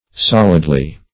Solidly \Sol"id*ly\, adv.